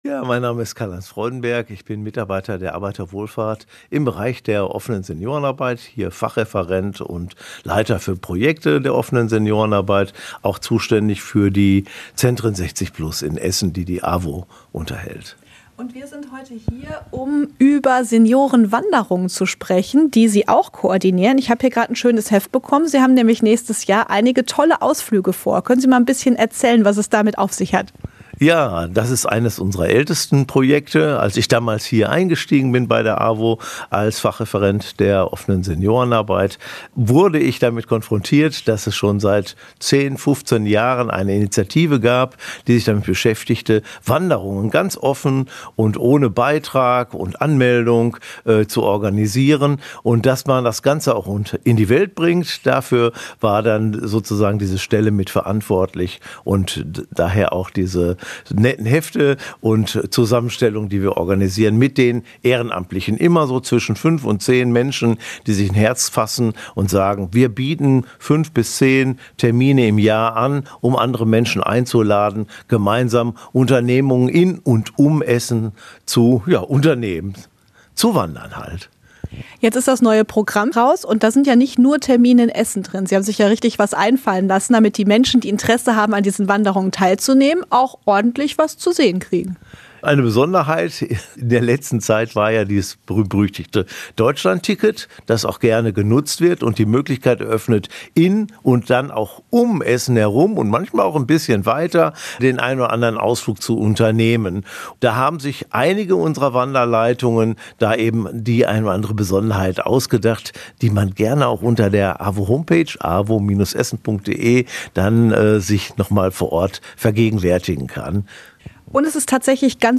int-awo-seniorenwanderungen-fuer-online.mp3